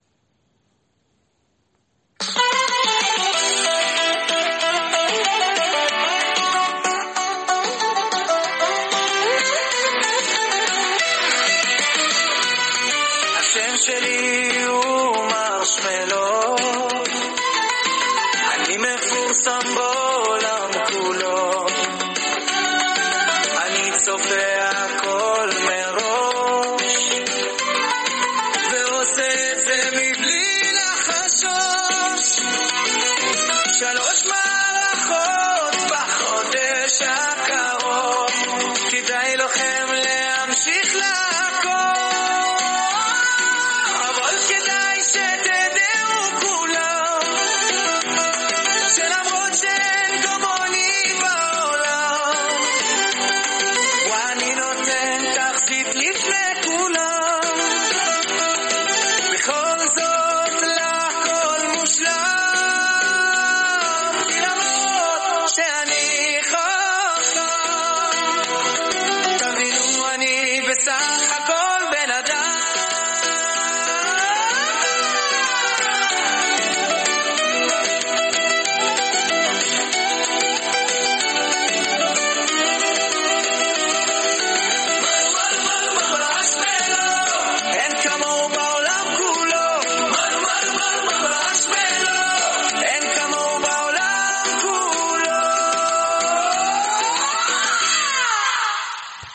וזאנר נוסף וקיצבי, בסגנון השירים של מרדכי שפירא, בתקוה שתהנו